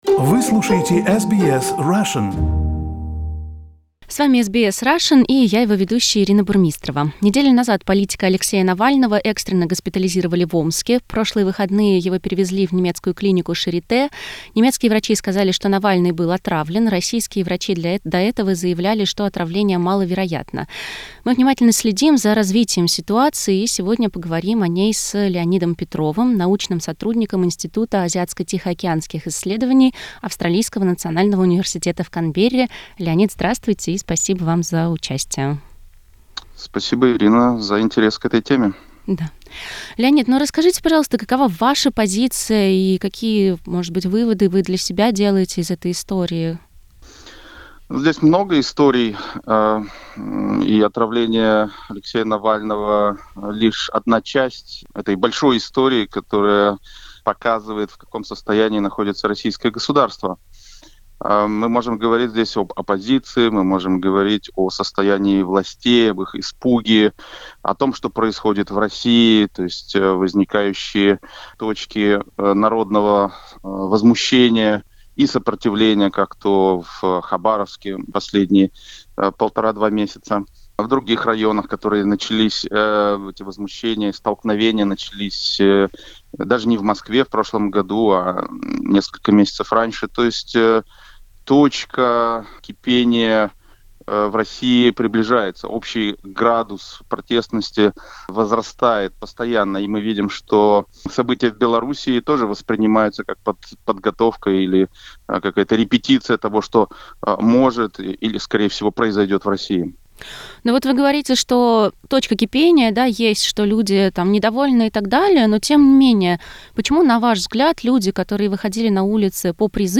Alexei Navalny remains in an coma in the German clinic. Here is the interview about the situation with the possible poisoning of the politician